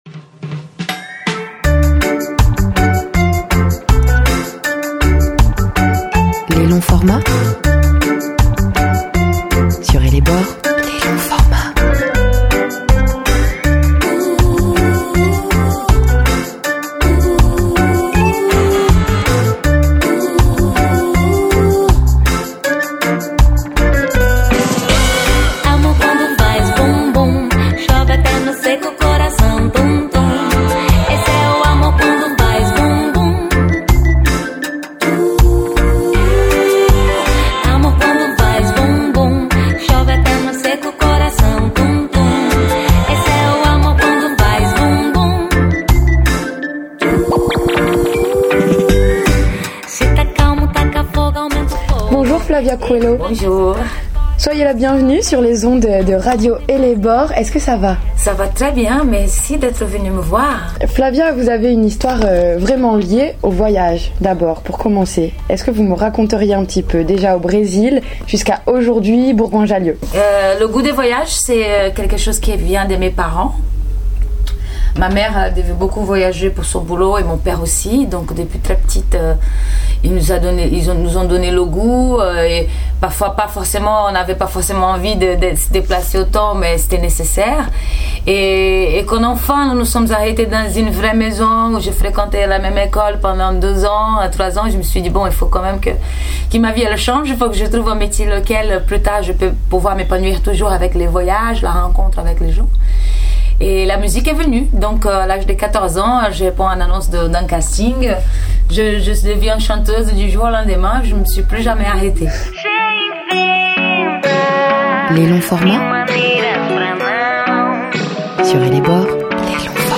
Nous nous sommes donc rencontrées juste avant son concert aux Abattoirs à Bourgoin-Jallieu, pour une interview pussy power. La lumineuse Flavia Coelho se dévoile et raconte son enfance au Brésil, son amour du voyage, les rencontres qui ont tout changé, ses colères, ses tatouages, ses engagements et son optimisme.